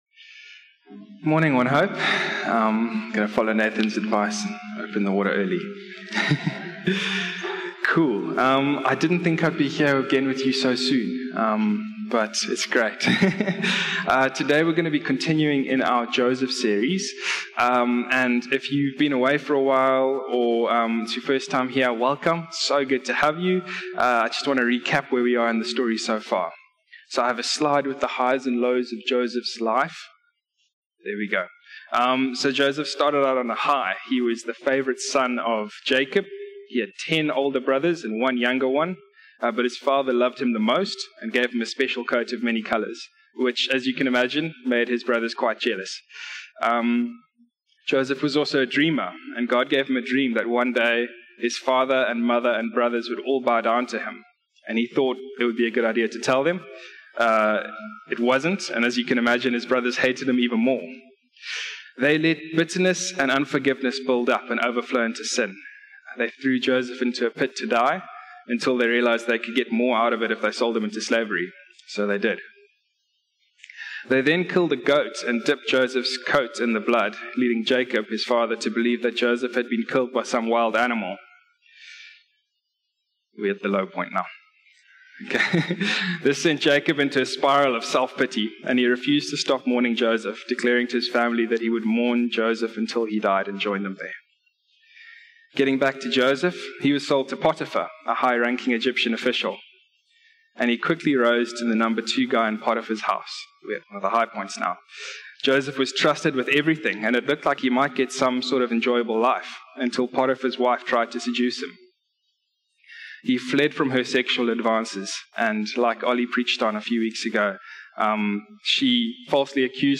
In his sermon on Sunday
One-Hope-Sermon-6-October-2024.mp3